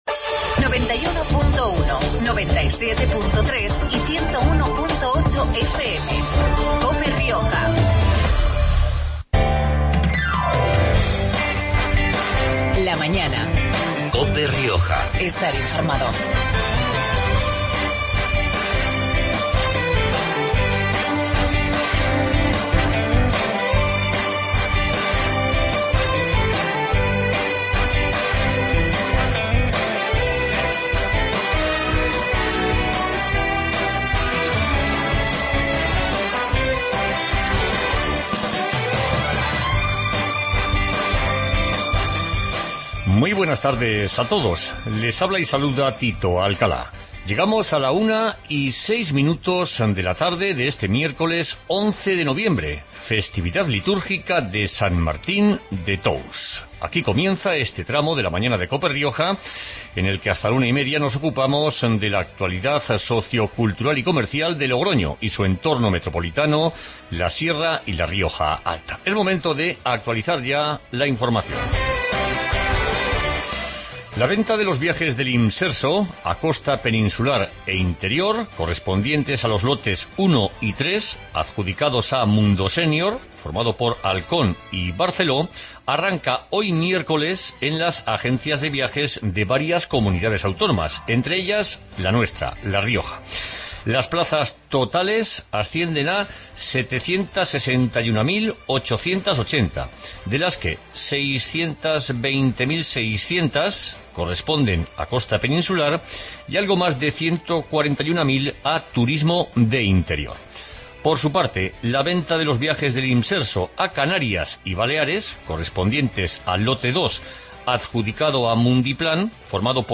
Magazine de actualidad riojana